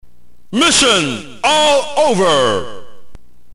narrator-2-9.mp3